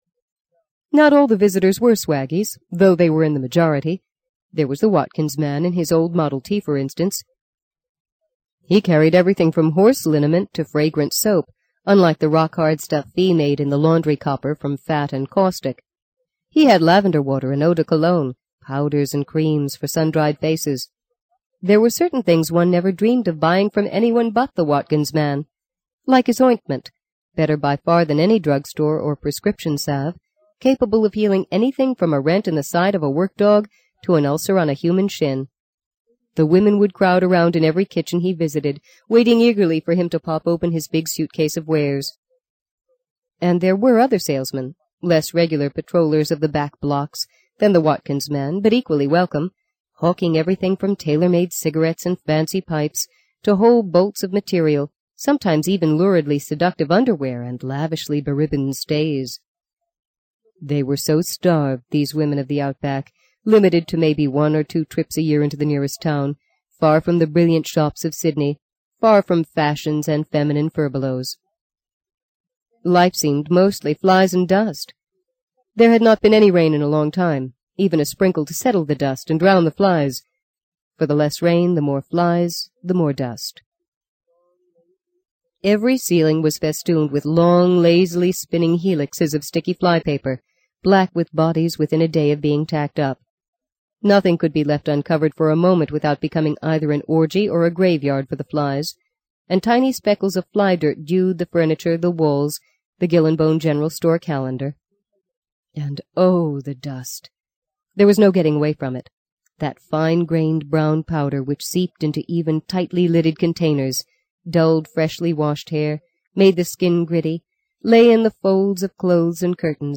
在线英语听力室【荆棘鸟】第六章 05的听力文件下载,荆棘鸟—双语有声读物—听力教程—英语听力—在线英语听力室